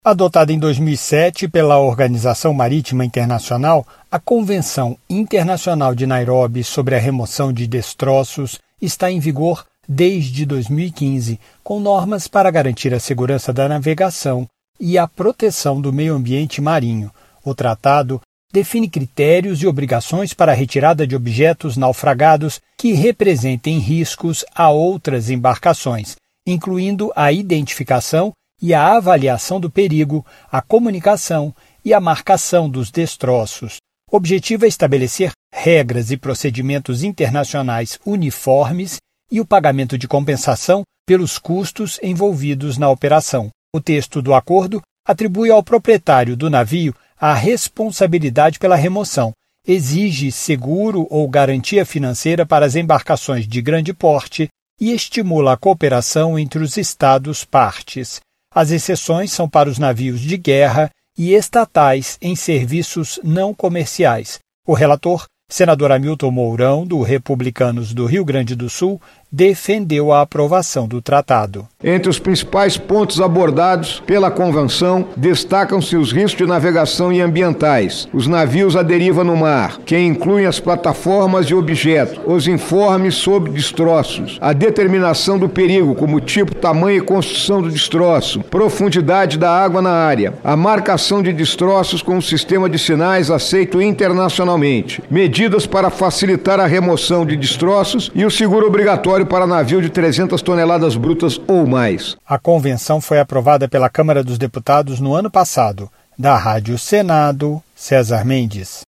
O relator, senador Hamilton Mourão (Republicanos-RS), explicou que a marcação dos destroços com sistema aceito internacionalmente; além da identificação da profundidade da água na área é responsabilidade do proprietário do navio.